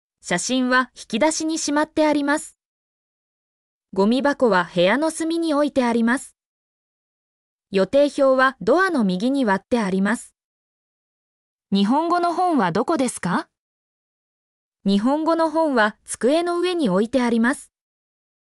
mp3-output-ttsfreedotcom-5_VDMLs3q4.mp3